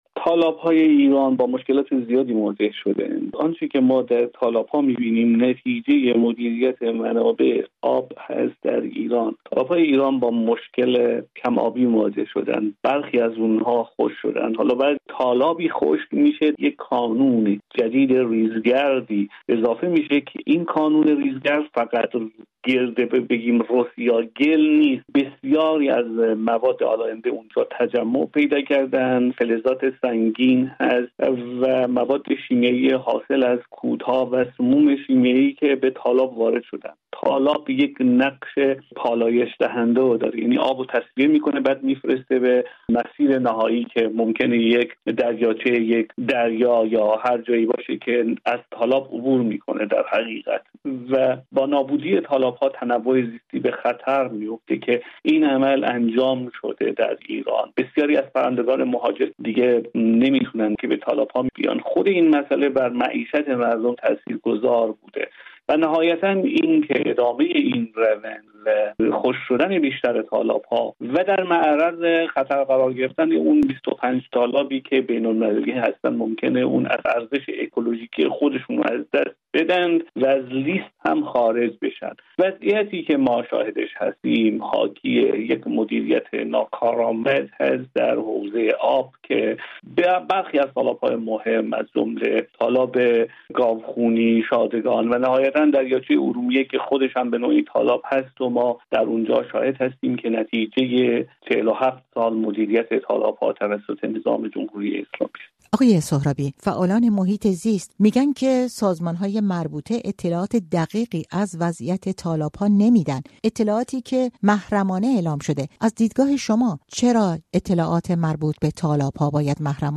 یک کارشناس محیط زیست: اطلاعات مربوط به تالاب‌ها در ایران «محرمانه» است
در گفت‌وگو با رادیو فردا